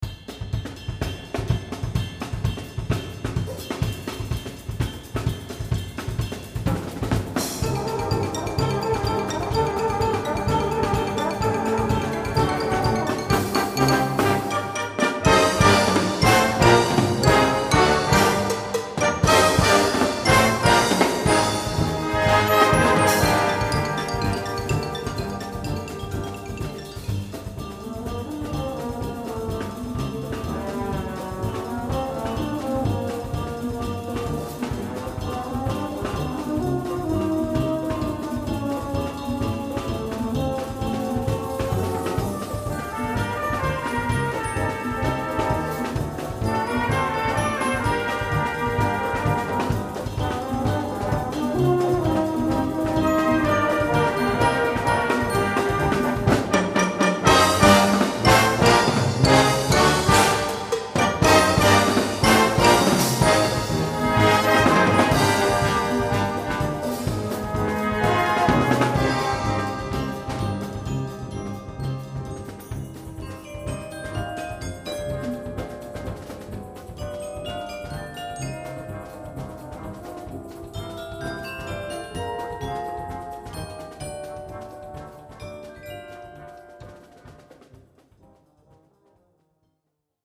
Brass Band